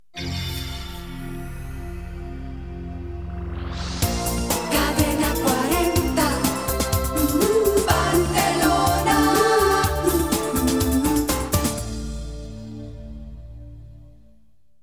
Indicatiu "Techno" de l'emissora
FM